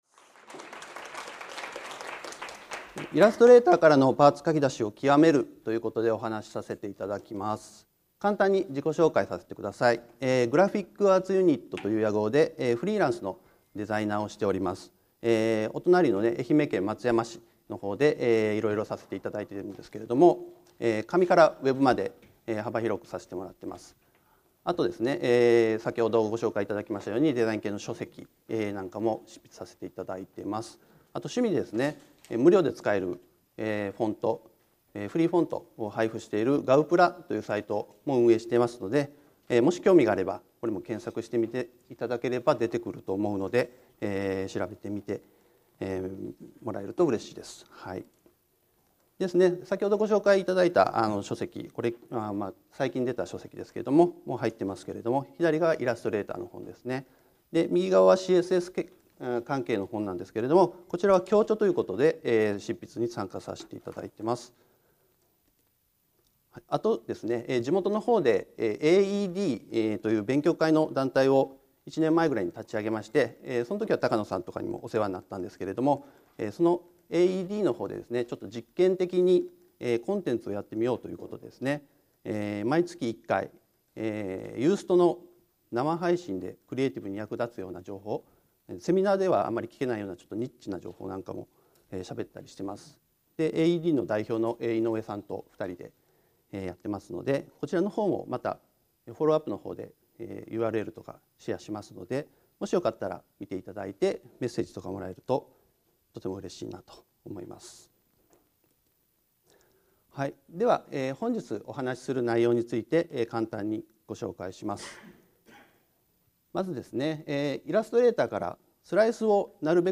全体的に駆け足気味になってしまい、お聞き苦しい点もあったかと思います。